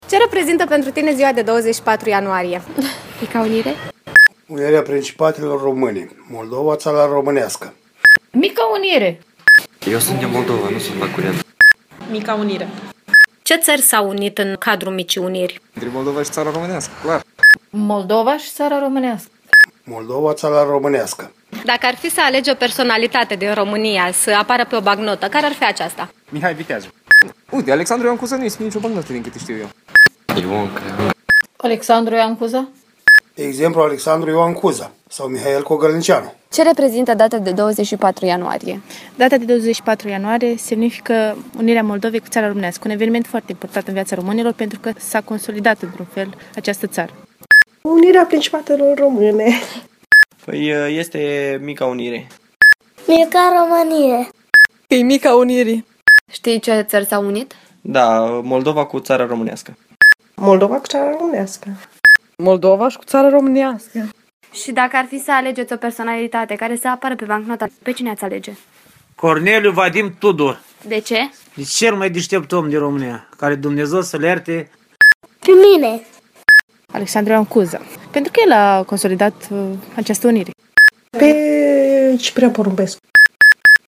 UNIRE-SONDAJ.mp3